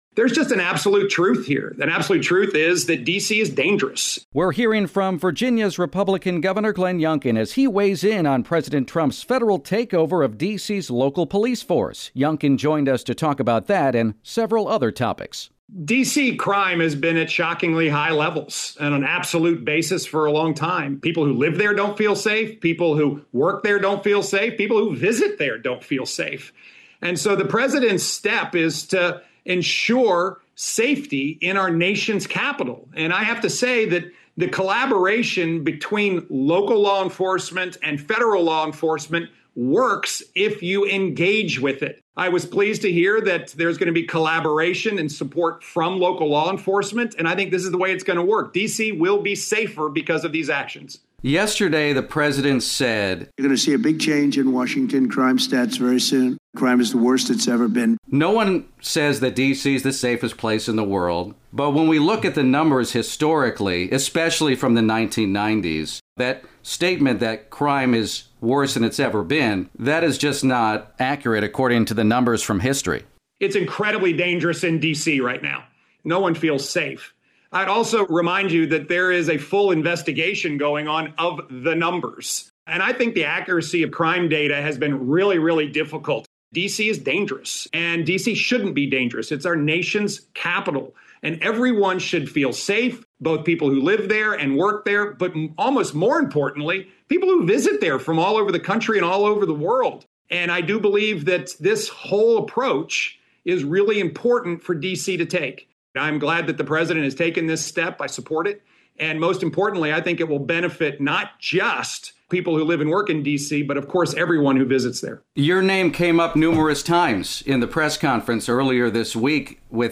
During a separate interview with WTOP on Thursday, Virginia Gov. Glenn Youngkin threw his support behind Trump, saying crime in D.C. is at “shockingly high levels” and what the president is doing will make the District safer.
youngkin-interview.mp3